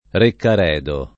Reccaredo [ rekkar $ do ]